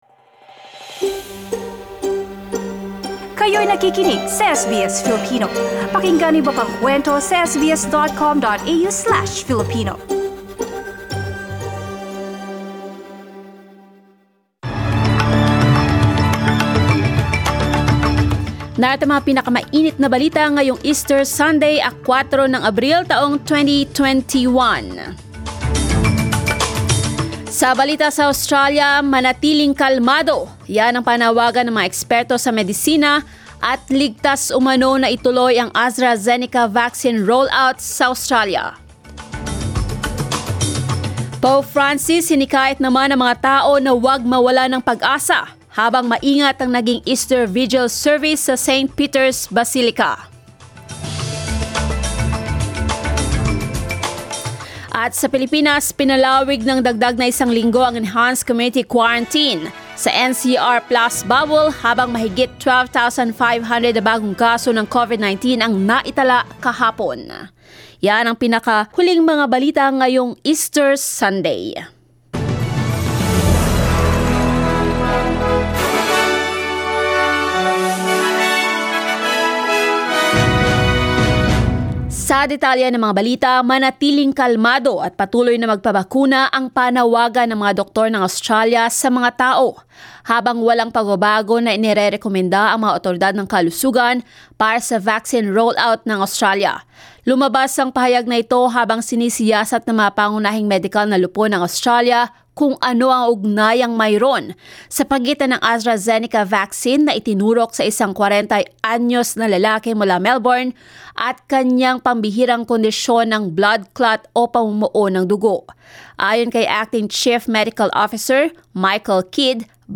SBS News in Filipino, Sunday 4 April